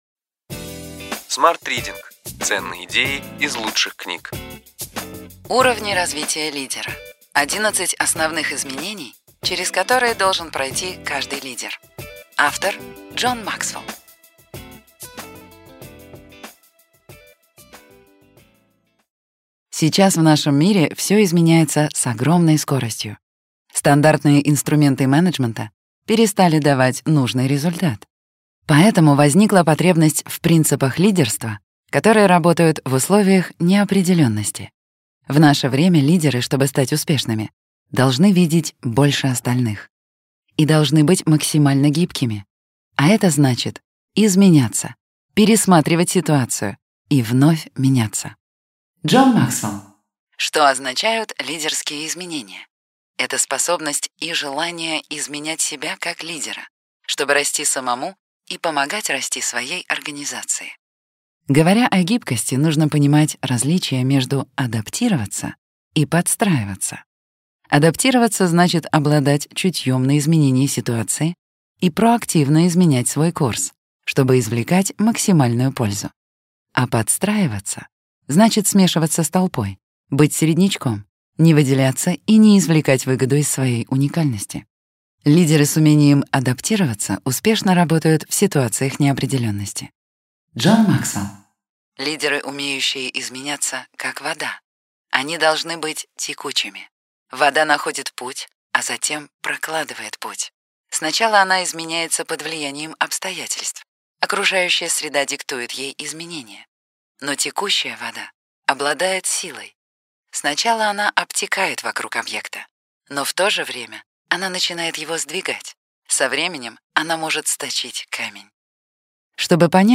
Аудиокнига Ключевые идеи книги: Уровни развития лидера. 11 основных изменений, через которые должен пройти каждый лидер.